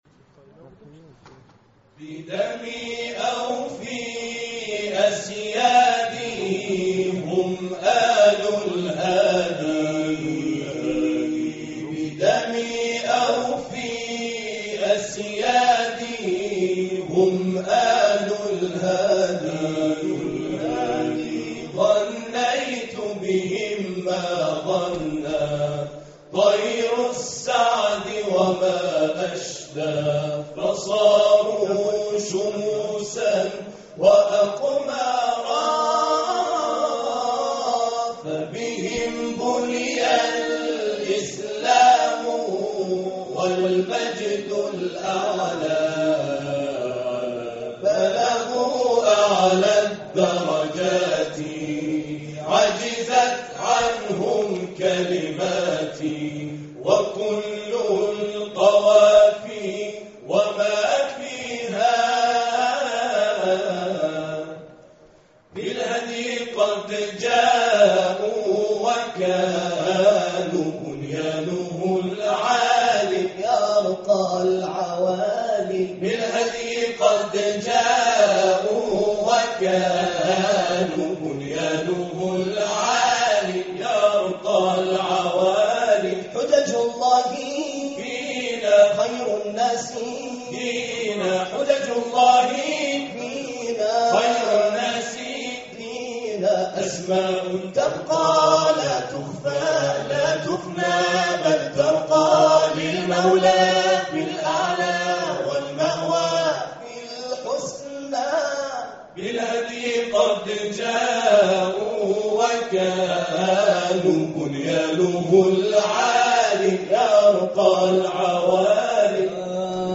تواشیح
گروه تواشیح باقرالعلوم